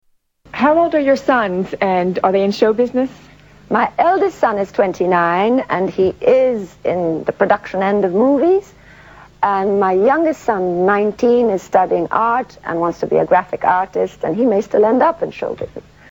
Tags: Audrey Hepburn clips Audrey Hepburn interview Audrey Hepburn audio Audrey Hepburn Actress